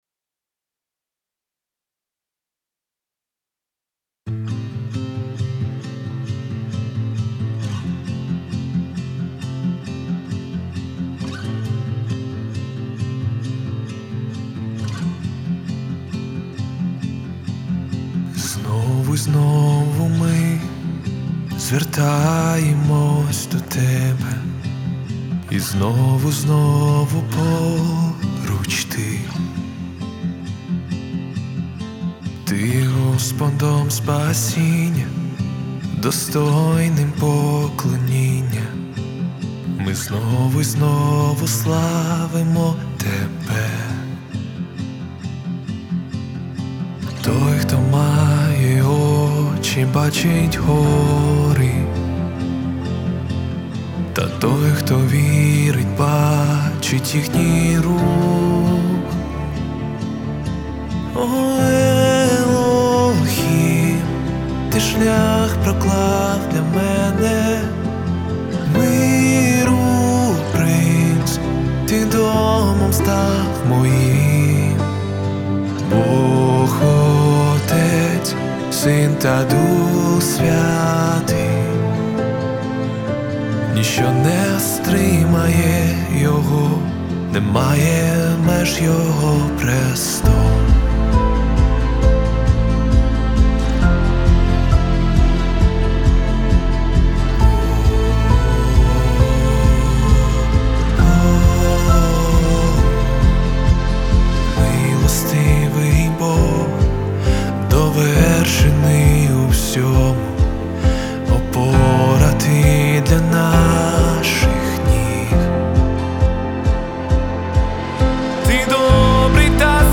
337 просмотров 349 прослушиваний 26 скачиваний BPM: 67